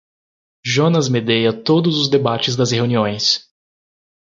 Pronunciado como (IPA)
/ˈʒõ.nɐs/